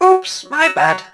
barley_throw_01.wav